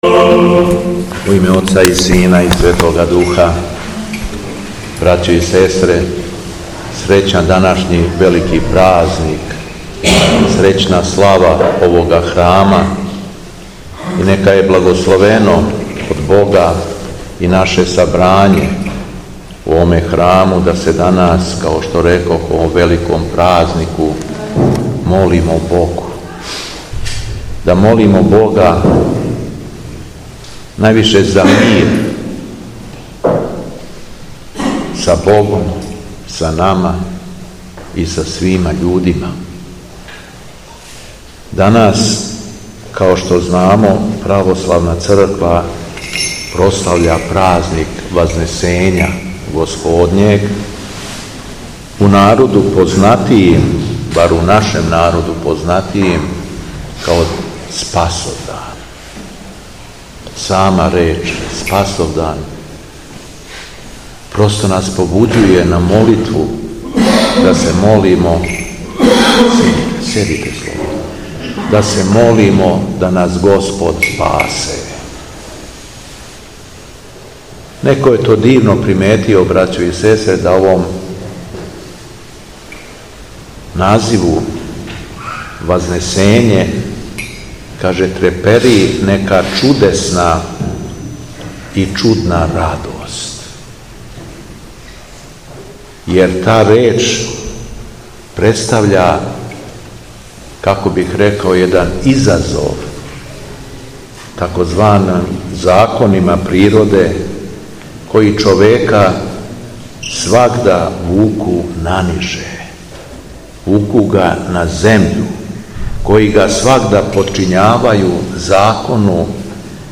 У храму посвећеном Вазнесењу Господњем у Ђуриселу литургијски је прослављена храмовна слава.
Беседа Његовог Високопреосвештенства Митрополита шумадијског г. Јована